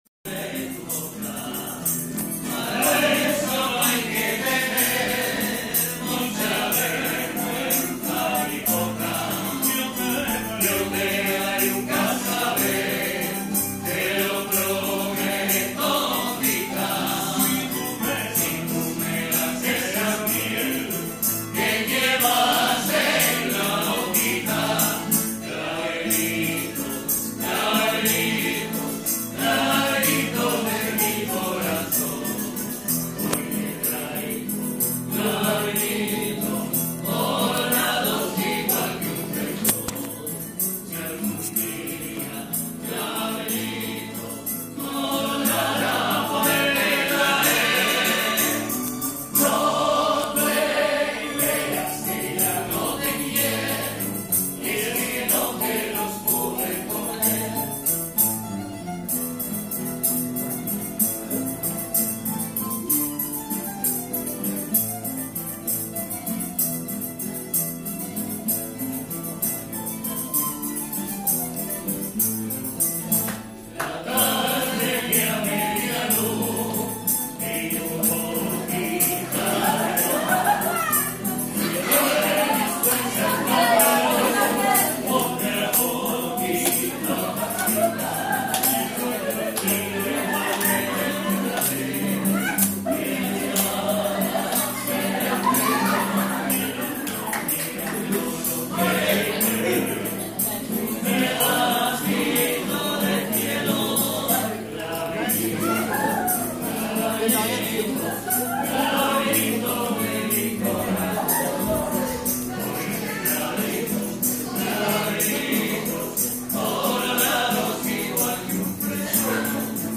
Para terminar, la tuna compuesta de seis hombres, llegó y cantó con guitarras.
Pour terminer, les six composant la « tuna » arrivèrent et chantèrent des chansons avec des guitares.
tunas.m4a